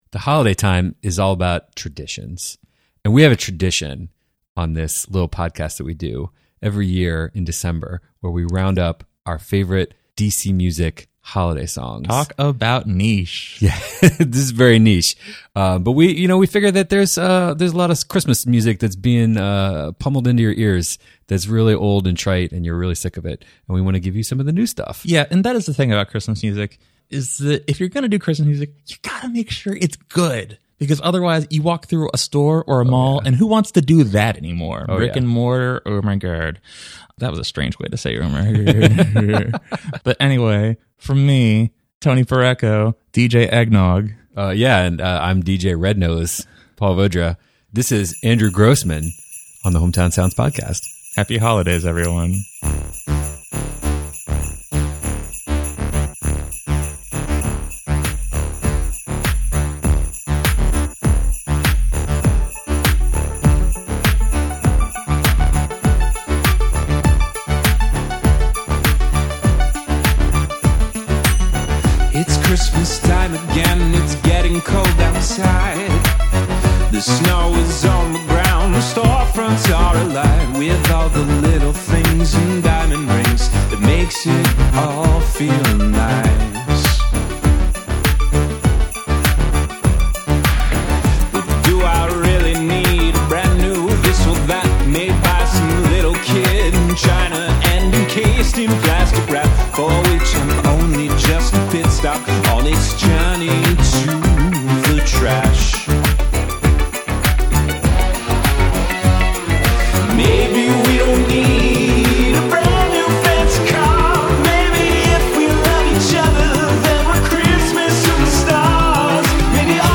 some choice selections of DC holiday music